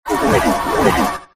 845-gulping.ogg